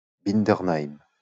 Bindernheim (French pronunciation: [bindəʁnaim]